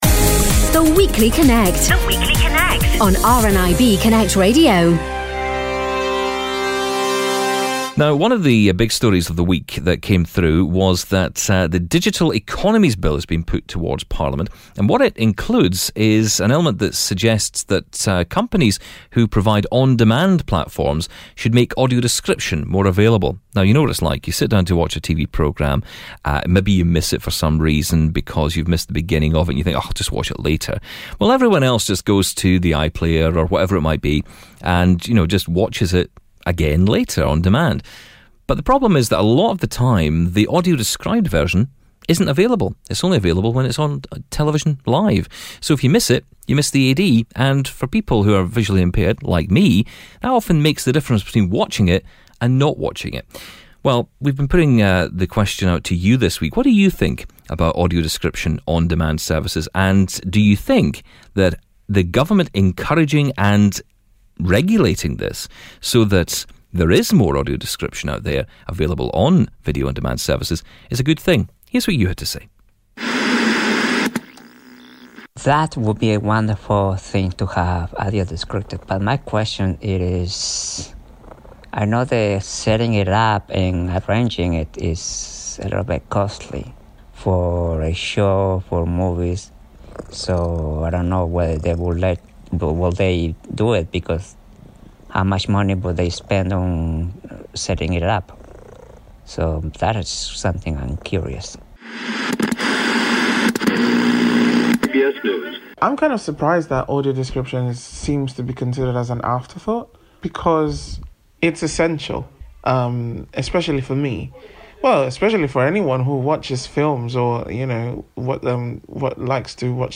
The community speak out about their feelings on audio description after the Government announced its Digital Economies Bill that will require on demand services to include AD in the same way as it is available on scheduled television.